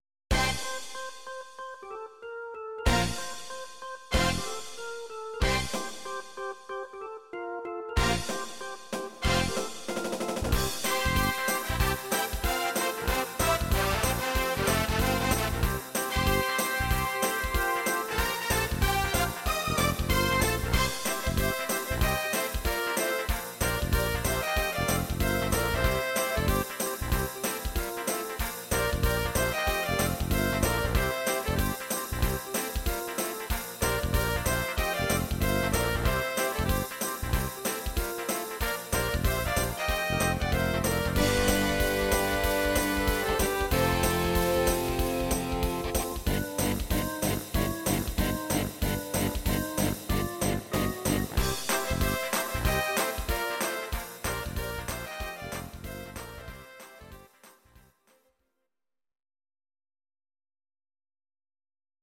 Audio Recordings based on Midi-files
Our Suggestions, Rock, 1980s
cover